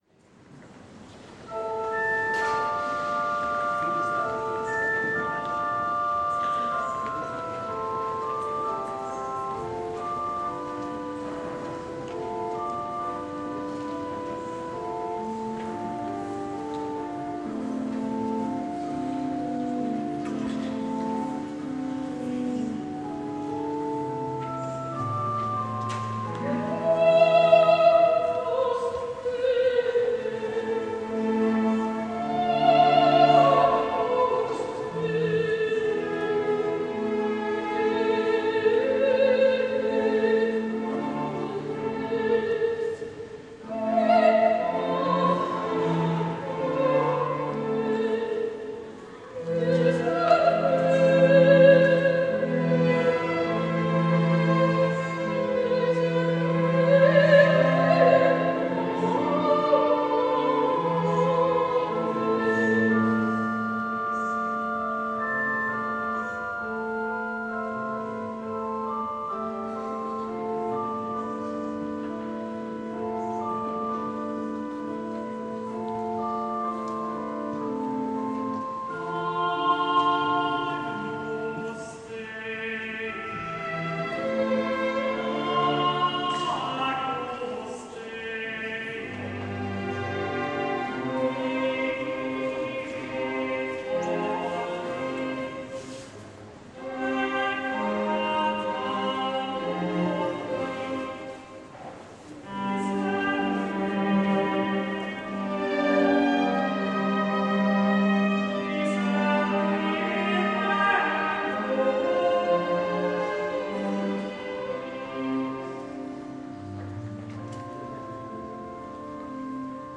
(Ostern)